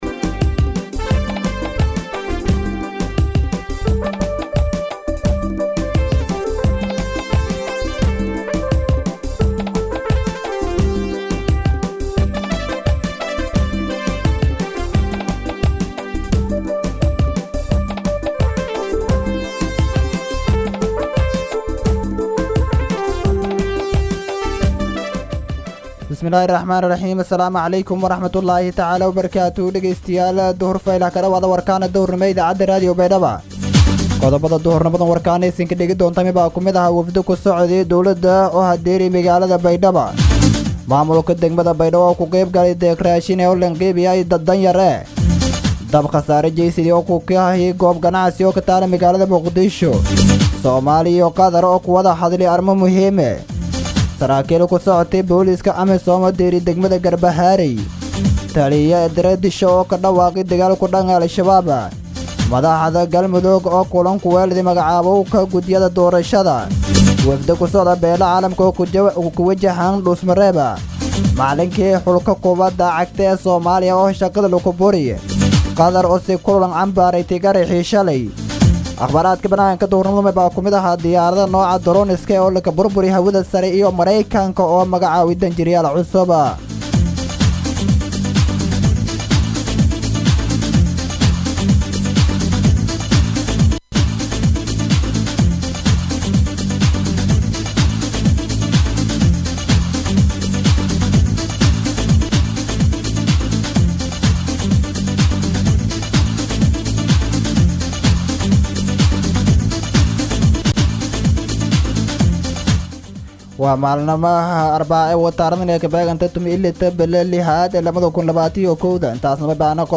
BAYDHABO–BMC:–Dhageystayaasha Radio Baidoa ee ku xiran Website-ka Idaacada Waxaan halkaan ugu soo gudbineynaa Warka maanta ee ka baxay Radio Baidoa.